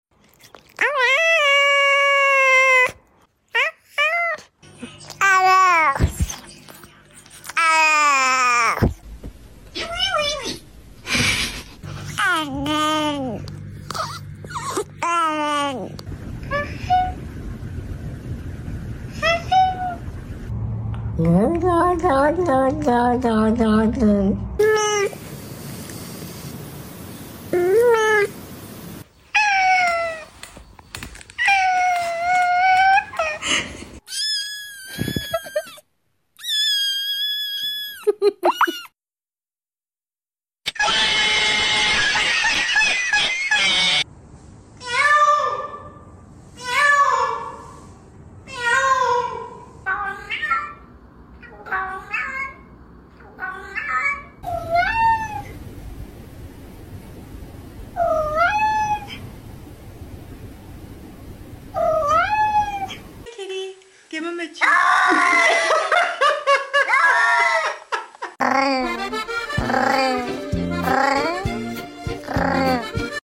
Listen to the healing sounds of cats